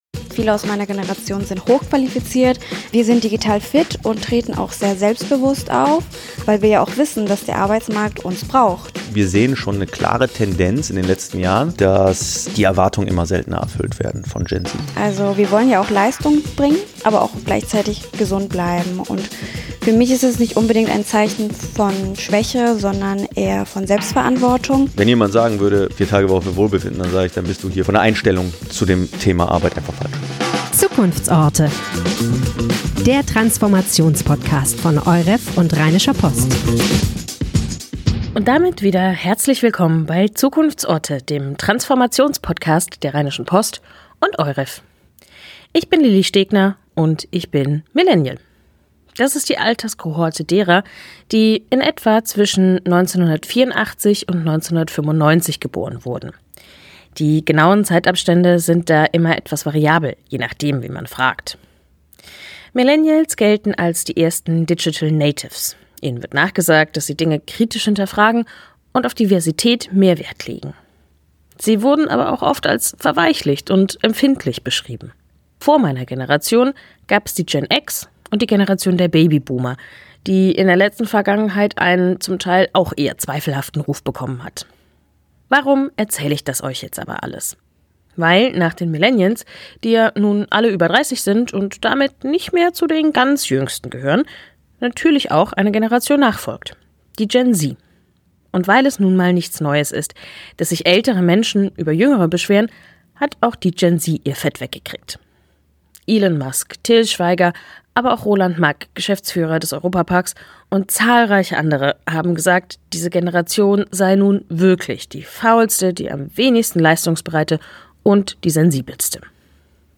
Doch was bringt diese Generation wirklich mit – frische Ideen und neue Ansprüche oder ein schwieriges Verhältnis zu Leistung und Verantwortung? Zwei Perspektiven in einem Gespräch über die Zukunft der Arbeit.